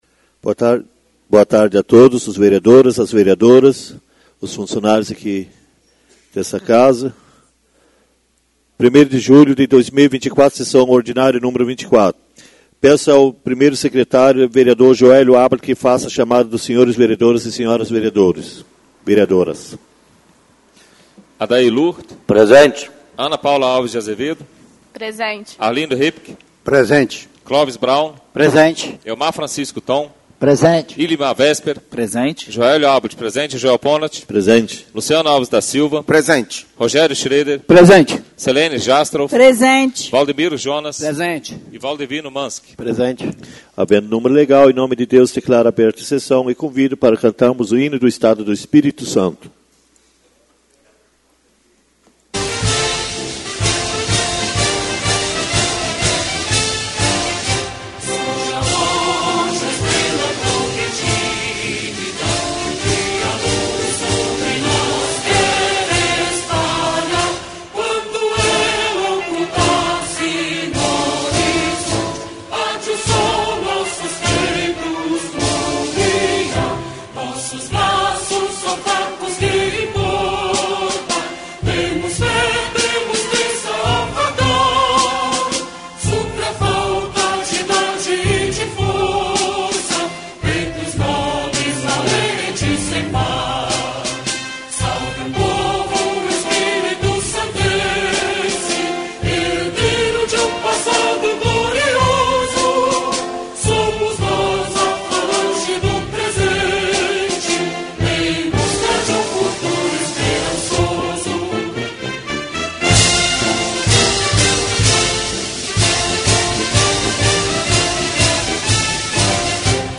Verso Bíblico - Lamentações capítulo 3 versículos 55 a 57 - Vereador Ilimar Vesper.
Hino do Estado do Espírito Santo.